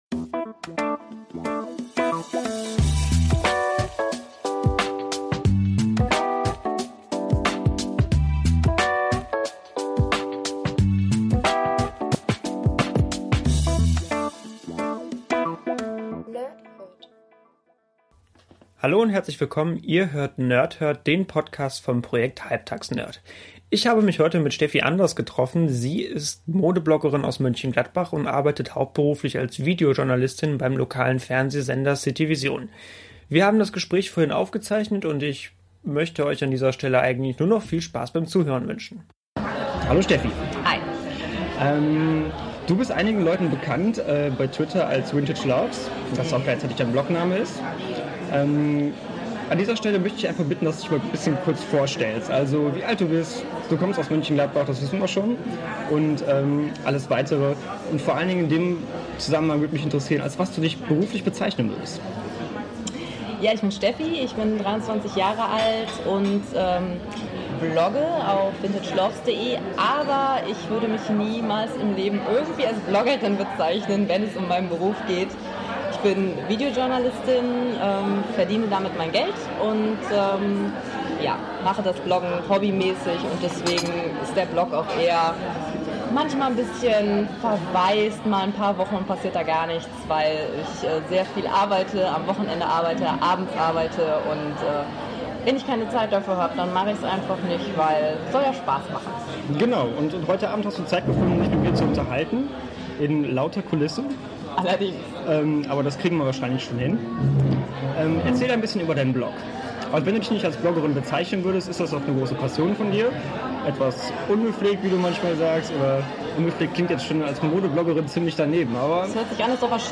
Ich entschuldige mich für die Qualität und hoffe, das Beste rausgeholt zu haben.